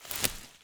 harvest_6.wav